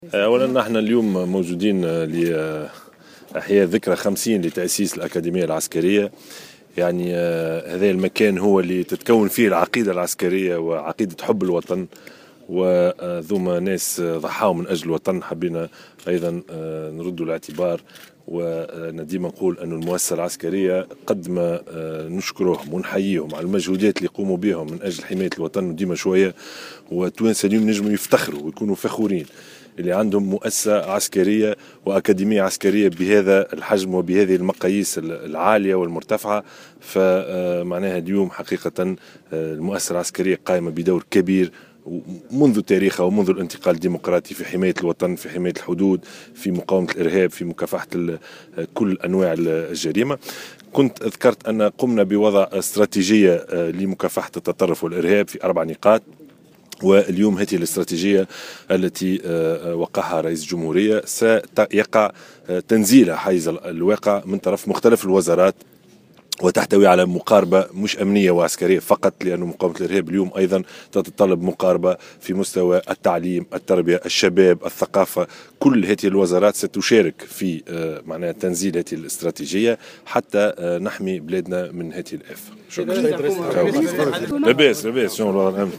قال يوسف الشاهد في تصريح لمراسلة الجوهرة "اف ام" اليوم على هامش الاحتفاء بمرور 50 عاما على تأسيس الأكاديمية العسكرية بفندق الجديد إن المؤسسة العسكرية تقوم بدور كبير في حماية الوطن وتأمين الحدود ومقاومة الارهاب داعيا التونسيين الى الافتخار بكونهم يملكون مؤسسة عسكرية واكاديمية بهذه المقاييس العالية على حد قوله.